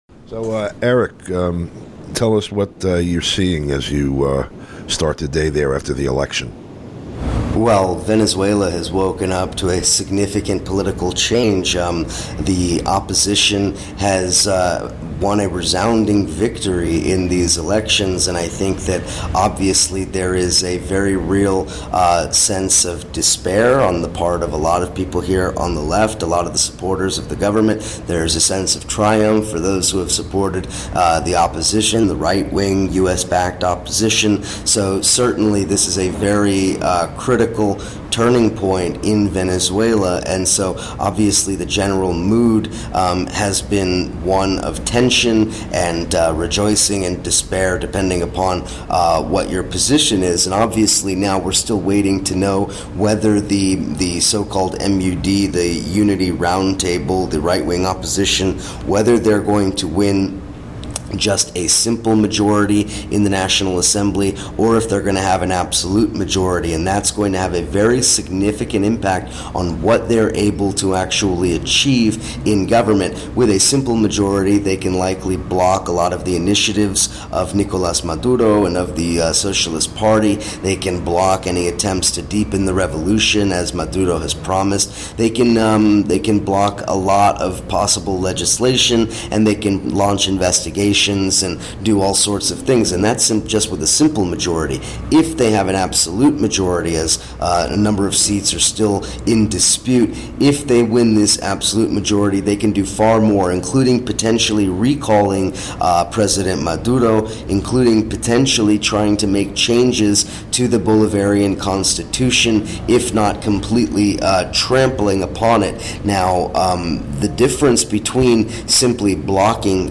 Program Type: Interview Speakers